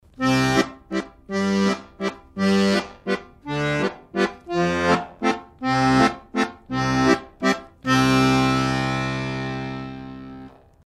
Звуки аккордеона
7. Чуть быстрее